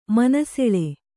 ♪ mana seḷe